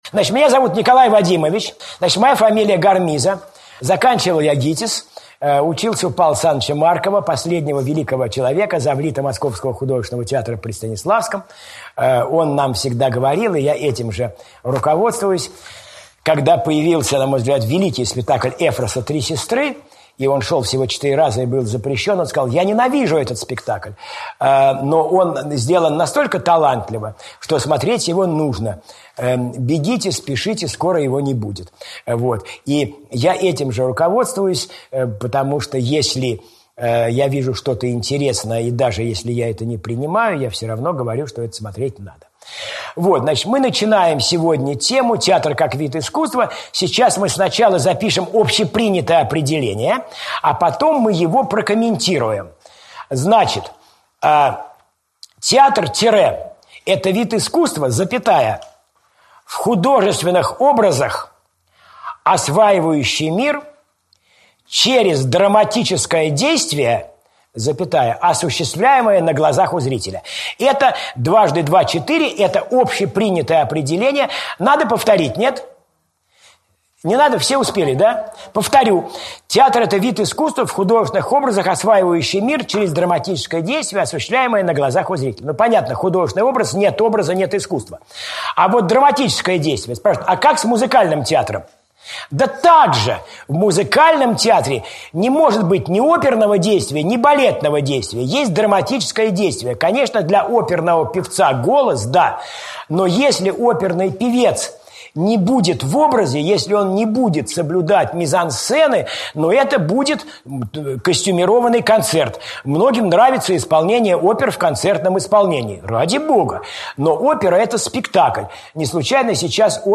На первой лекции речь пойдет о специфике театрального искусства, его жанрах и разновидностях.